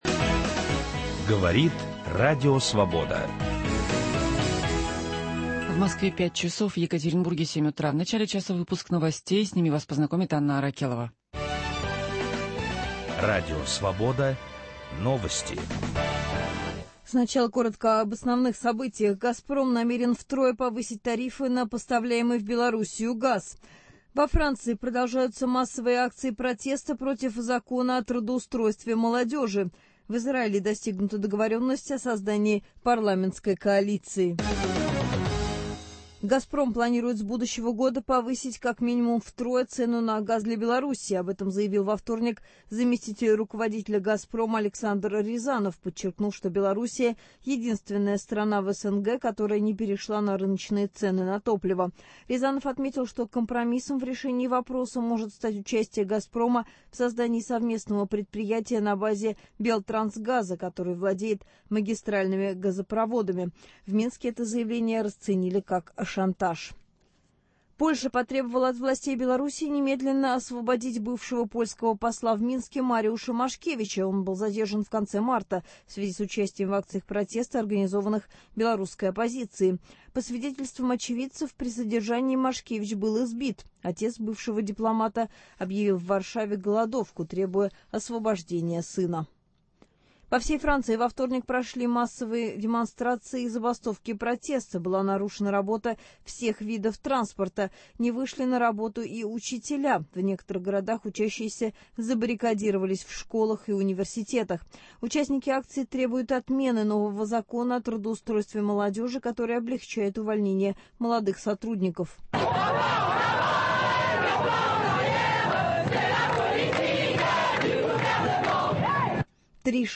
Первый час утреннего выпуска информационной программы «Время Свободы» подготовлен екатеринбургской студией «РС» в сотрудничестве с омской телерадиокомпанией «Антенна – 7». Водители частных маршрутных такси в городе Первоуральске Свердловской области объявили голодовку. Они считают, что лишились работы из-за незаконных действий городской администрации.